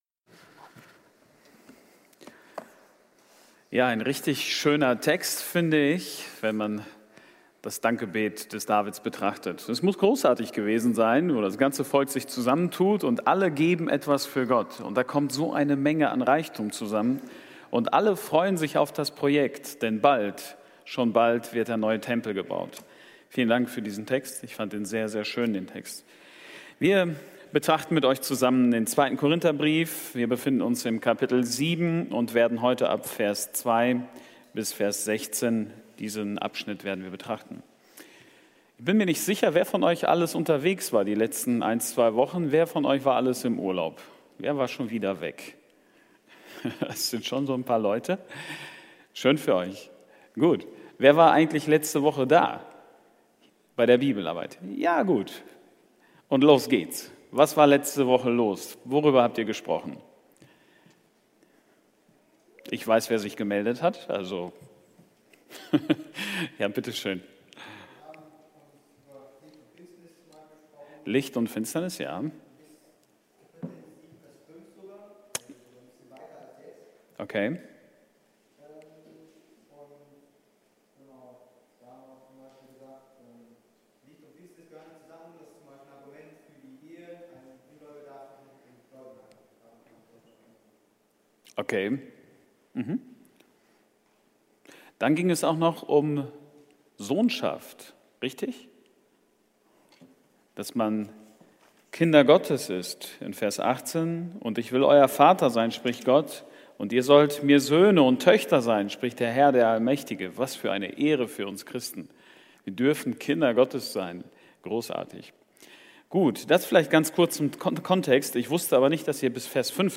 Bibelarbeit – 2 Korinter 7,2-17